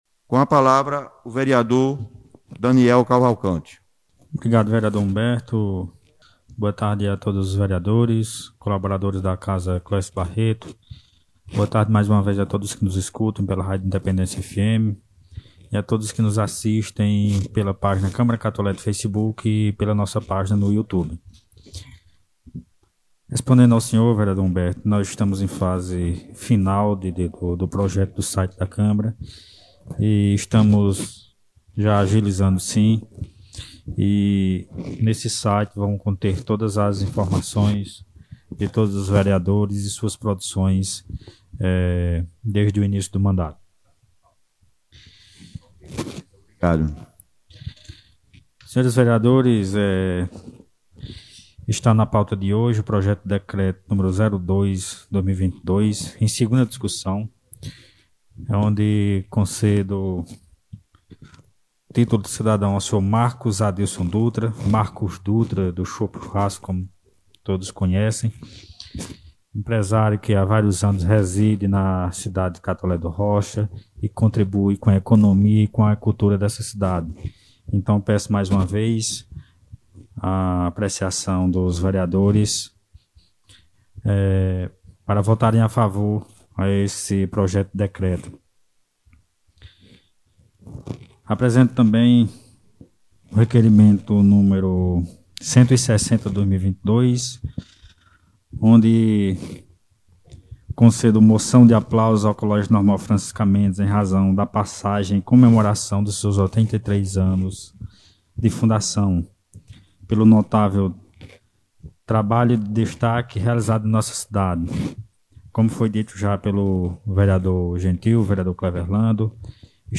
O presidente da Câmara Municipal de Catolé do Rocha, vereador Daniel Cavalcante, na Sessão Ordinária realizada na tarde desta segunda – feira, dia 18 de abril de 2022, apresentou dois…